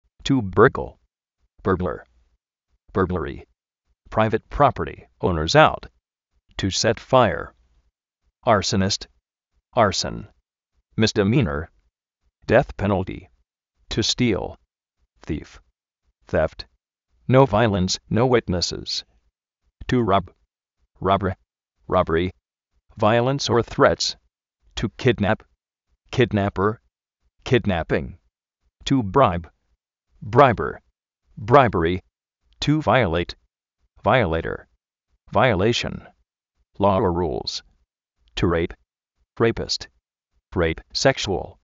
misdimínor
déz pénalti